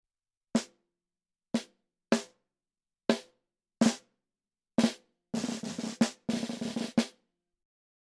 Microphone Shootout – Snare Drum Edition
In order to eliminate as many variables as we could, the microphones were set up in as close to the same spot as we could get and I played every test the same way: Center hit, off-center hit, flam, nine-stroke roll.
We had two of almost all of these microphones, so the snare drum is double-miked for all but a few tests (which I’ll disclose when we get to them) – one microphone on the top head, the other on the bottom placed right around the snares themselves.
The first microphone listed is panned hard left and the second is hard right.
Our first matchup is the ol’ standby facing one of its many challengers – Shure SM57 – vs. Blue EnCore 100i.
snare-mic-shootout-1.mp3